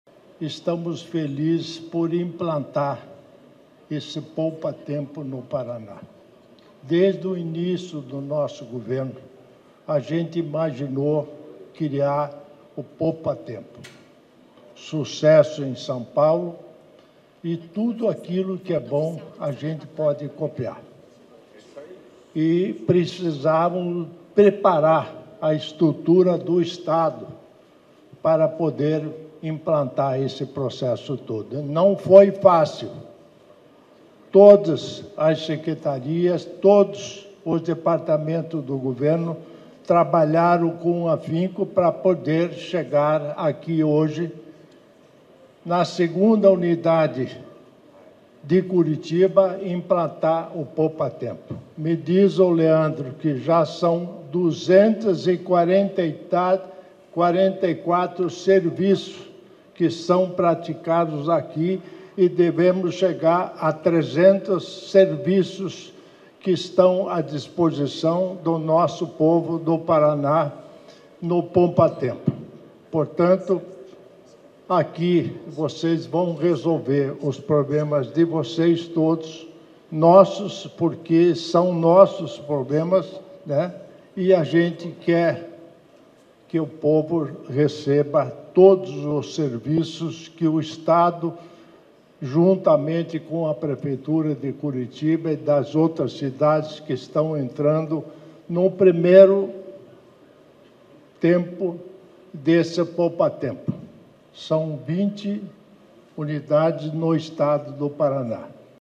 Sonora do vice-governador Darci Piana sobre a segunda unidade do Poupatempo Paraná em Curitiba